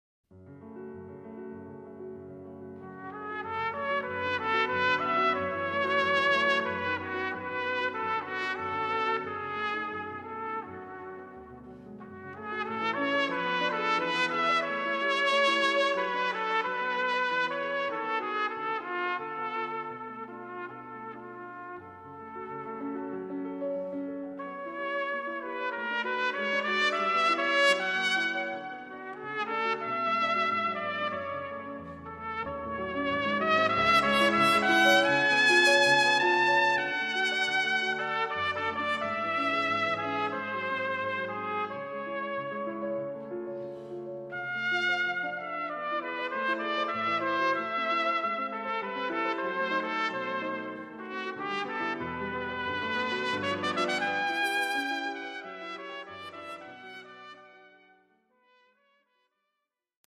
Instrumentalnoten für Trompete